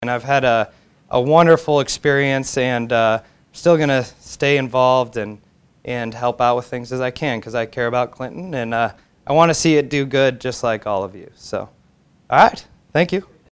Three Clinton City Council members and the Mayor who were not re-elected in November were honored at the final meeting of the year.